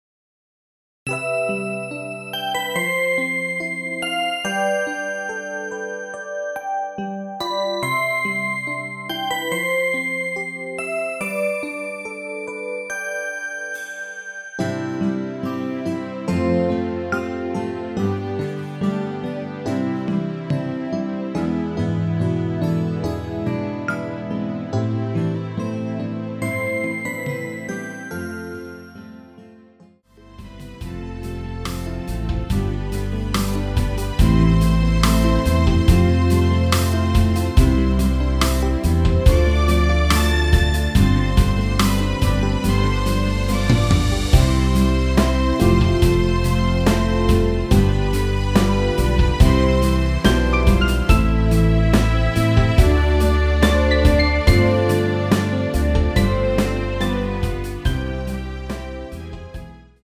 MR 입니다.
2절과 간주 없이 바로 진행이 됩니다.
(B키)동영상과 같은키를 원하실경우 -1키(B키)를 이용 하시면 되겠습니다.
앞부분30초, 뒷부분30초씩 편집해서 올려 드리고 있습니다.